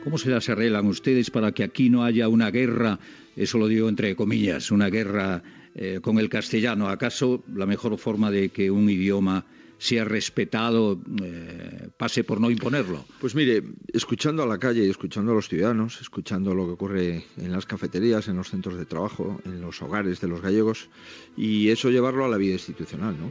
Fragment d'una entrevista al polític del Partido Popular Alberto Núñez Feijóo
Info-entreteniment